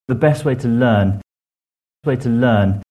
Illustrating the difference between this and native weight loss, here are some native speakers saying phrases containing way to: